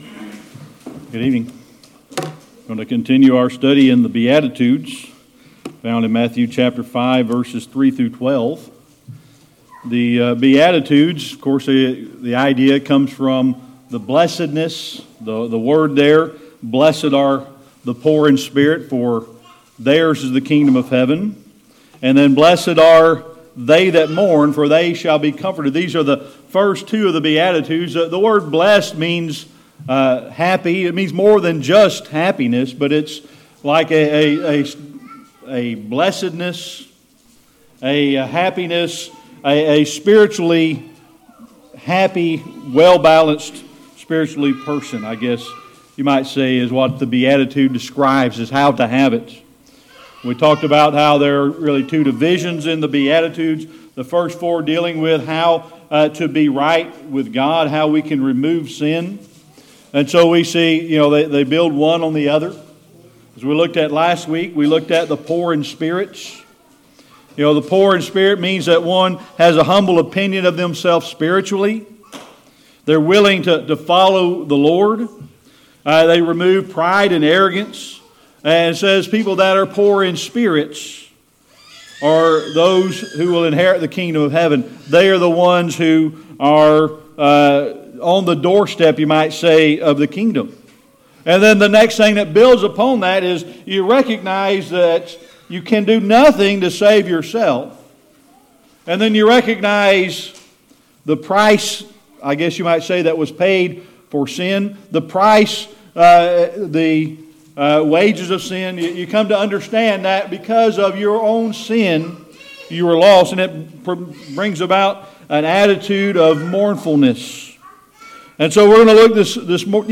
Matthew 5:4 Service Type: Sunday Evening Worship Continuing our study of the Beatitudes found in Matthew 5:3-12.